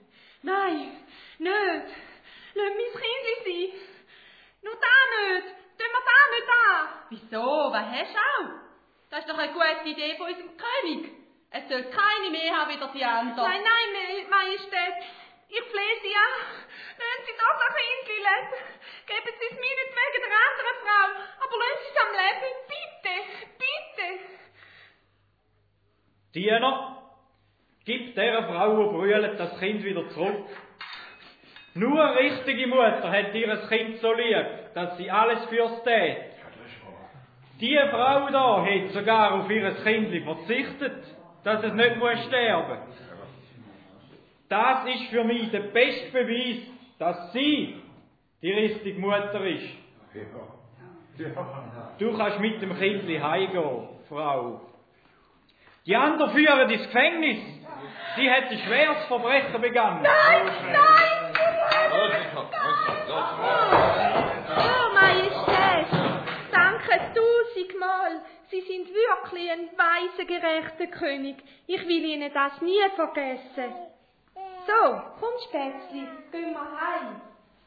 Die Königin von Saba muss eingestehen, dass sie so viel Reichtum und Weisheit noch nirgends gesehen hat wie bei König Salomo. Ein spannendes Hörspiel über das aussergewöhnliche Leben dieses bekannten Königs.
Hörspiel-AlbumBestellen